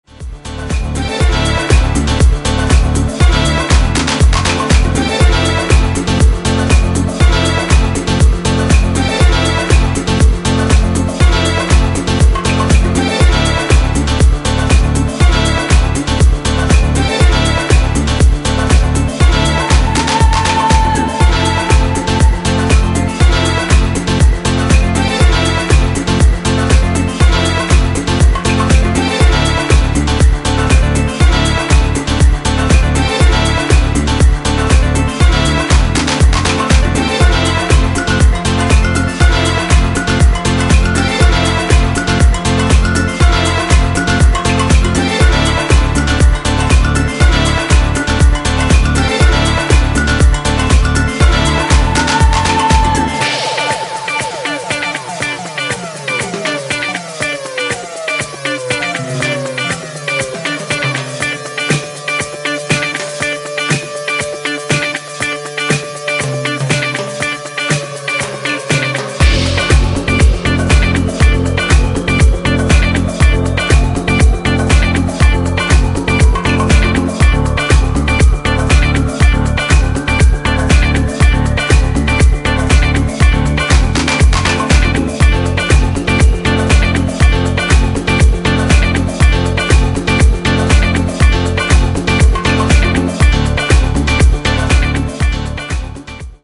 ブギーでソウルフルなディスコ、ファンク、ハウス、を散りばめたグレイト過ぎる好内容です！